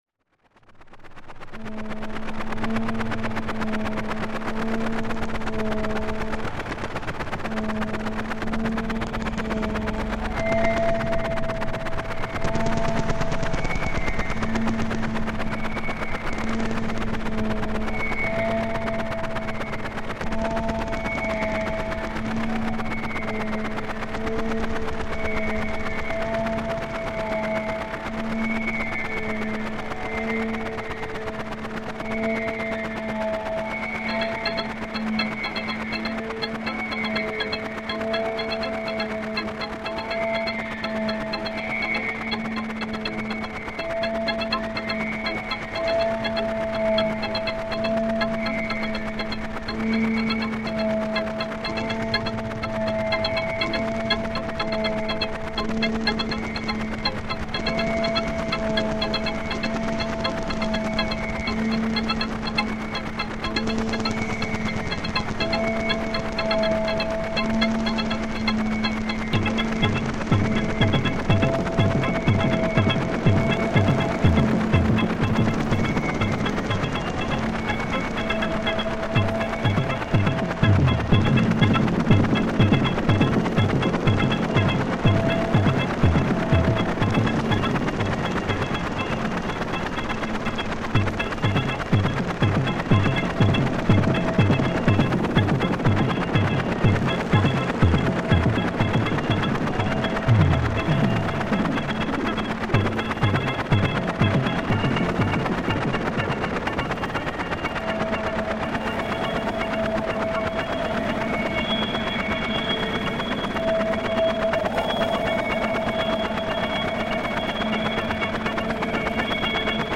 These are all from self playing patches (noodles).
These were all run on a samplerate of 48k with a control rate decimation of 32 to make it possible to have large patches.